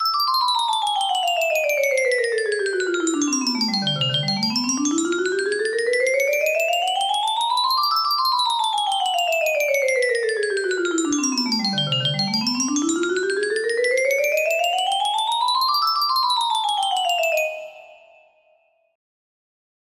Unknown Artist - Bryar music box melody